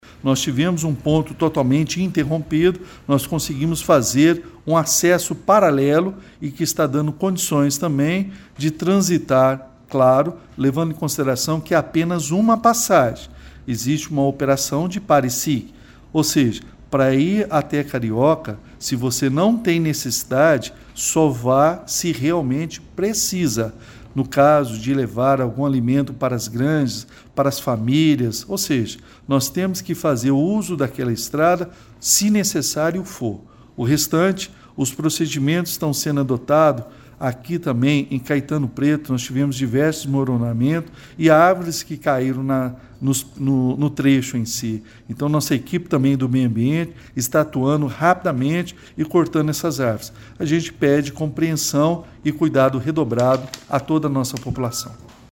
É o que afirma o prefeito Elias Diniz (PSD).